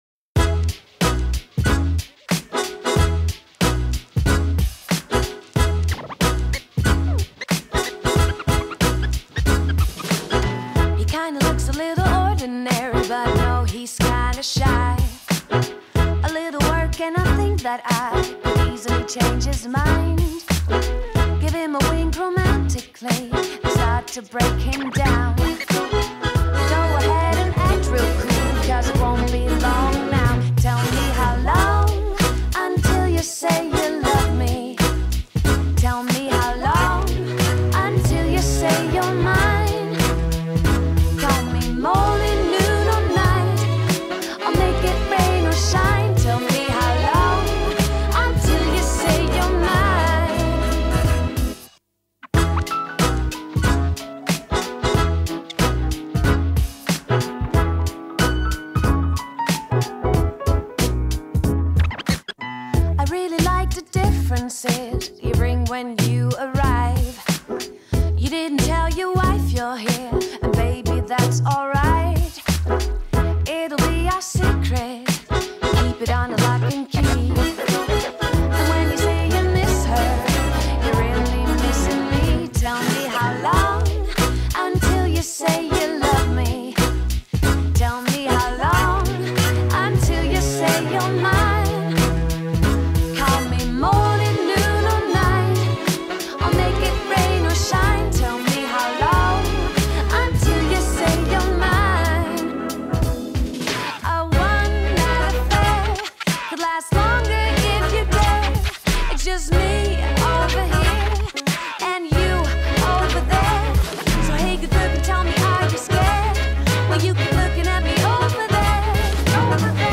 esta holandesa que canta jazz